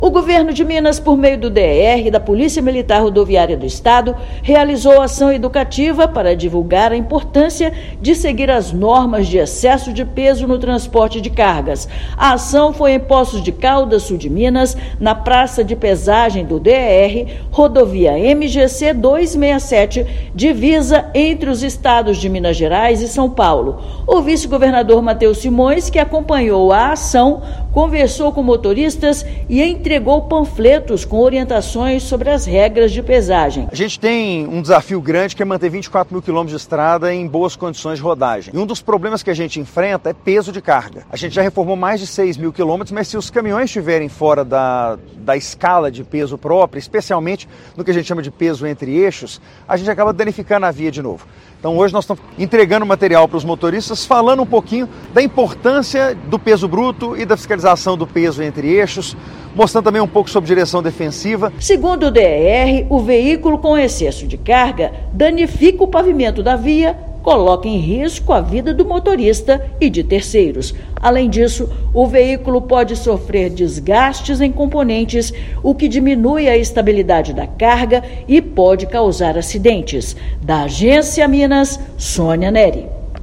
[RÁDIO] Governo de Minas promove ação educativa no Sul do estado para alertar sobre a importância de respeitar o limite do peso no transporte de carga
Vice-governador acompanhou iniciativa realizada na praça de pesagem em Poços de Caldas e ressaltou a importância da fiscalização para a segurança das estradas mineiras. Ouça matéria de rádio.